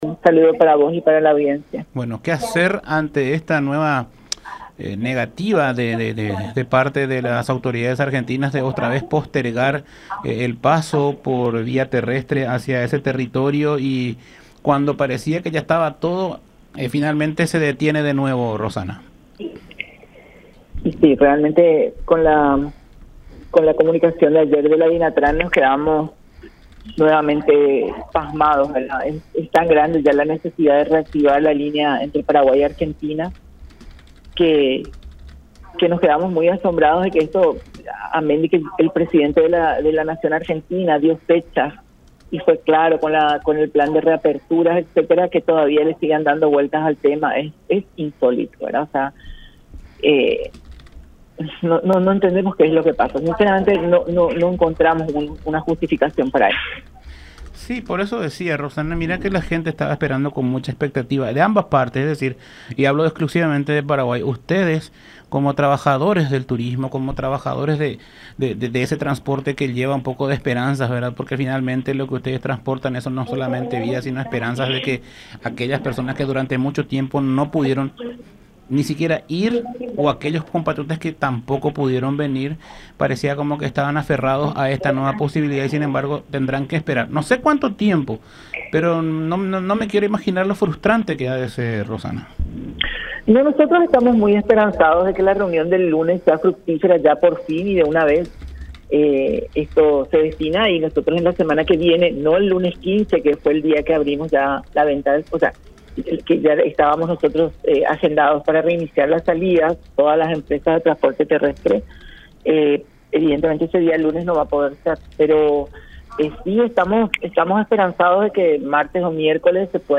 en conversación con Todas Las Voces por La Unión